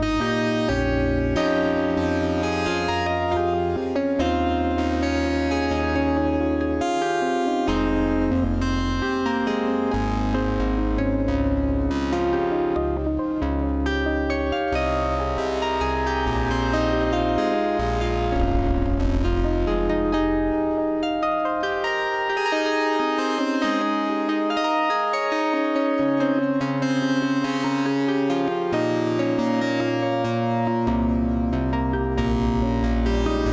I love the dynamic articulation in this one. Clean attack to blossoming mushy fuzz brass according to velocity